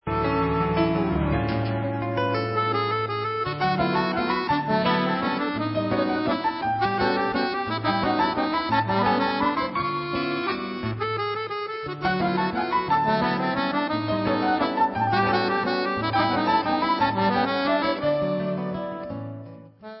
LIVE IN BRUSSELS